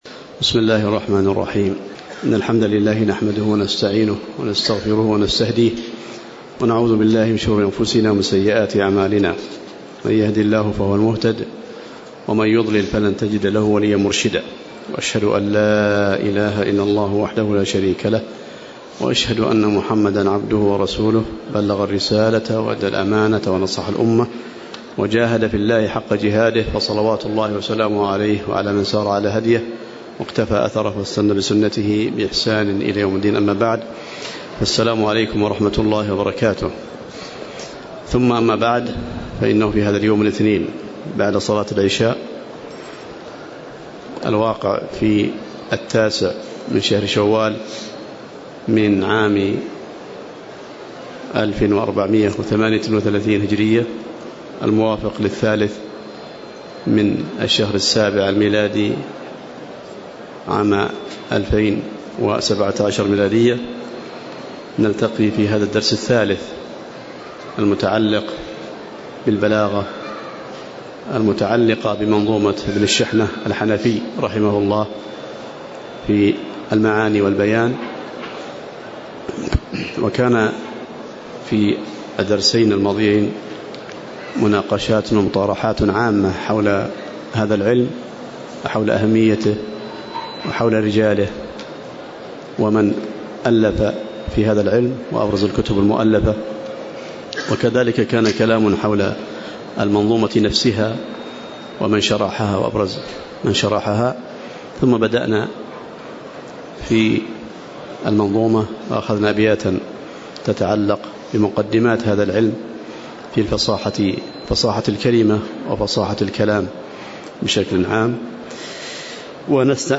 تاريخ النشر ٩ شوال ١٤٣٨ هـ المكان: المسجد النبوي الشيخ